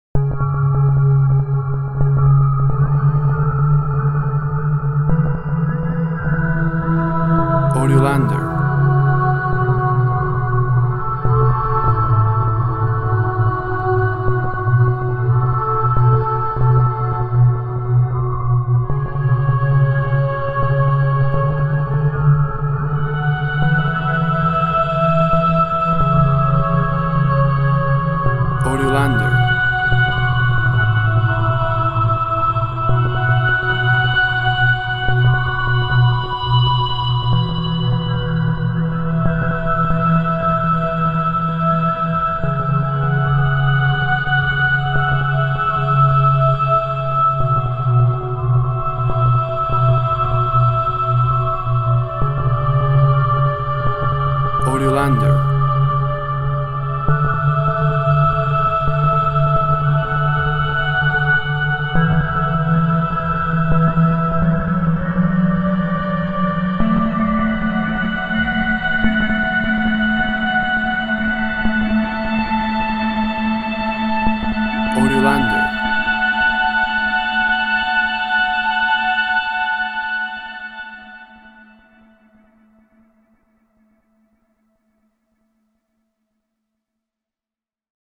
Tempo (BPM) 75